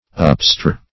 Upstir \Up"stir`\, n.